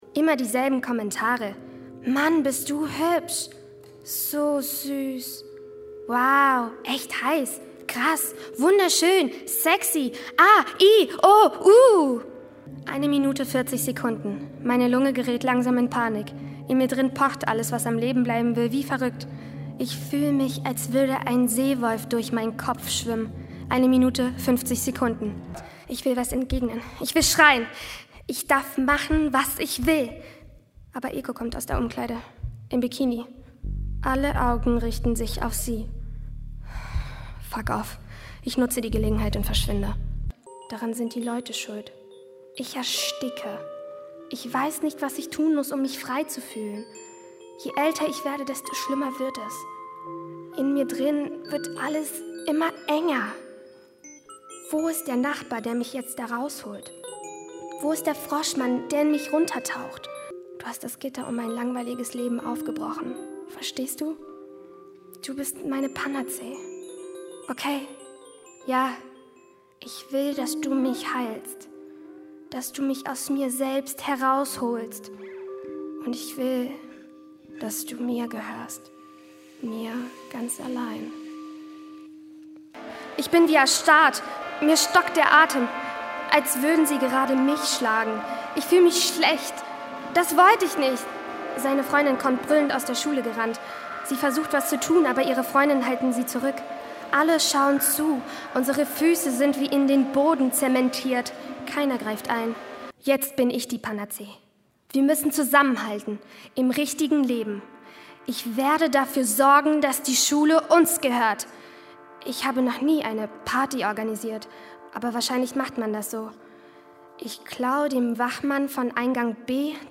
Hörprobe_UnterWasser_aufgeregteStimme.mp3